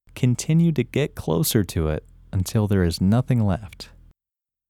IN – First Way – English Male 26
IN-1-English-Male-26.mp3